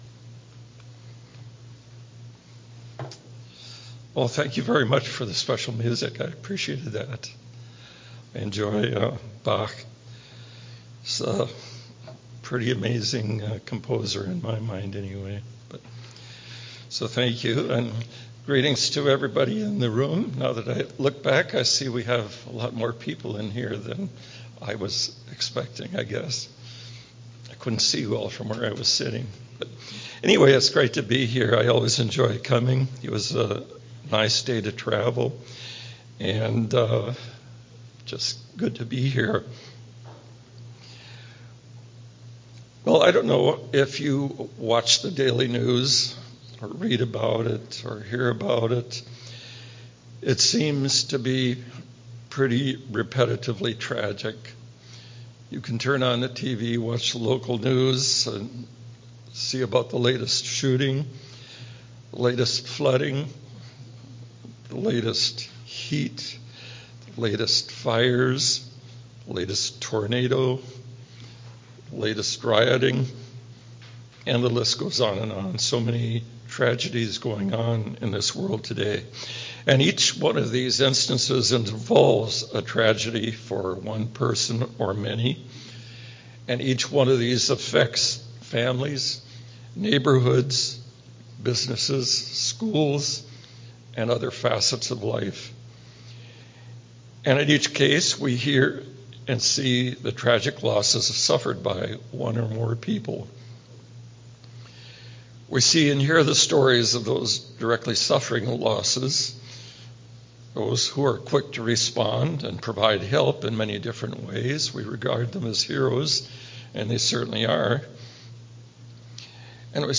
This sermon discusses the belief that God is intimately aware of each individual's life and challenges. It calls for empathy and support for those affected by disasters, urging listeners to bear each other's burdens as instructed by Gods word.